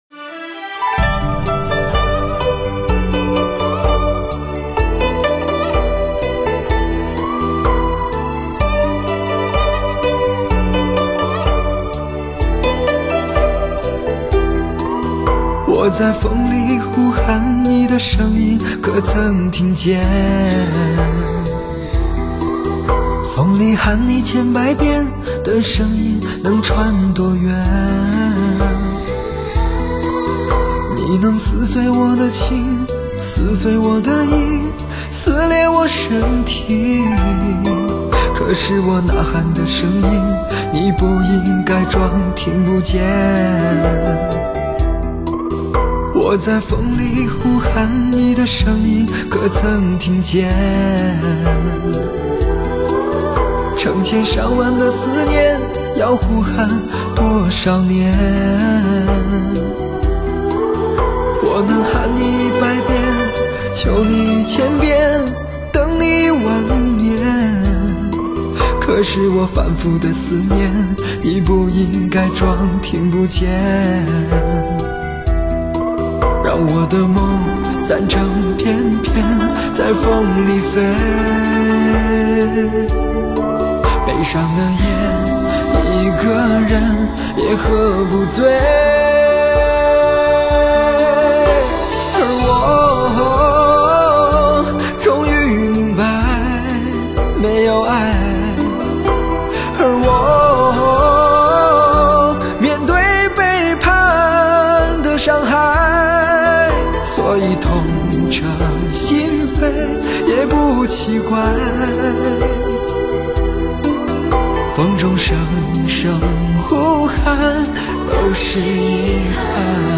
深情动容  真情的呐喊  沙哑的倾诉  绝对震撼每一位聆听者的心弦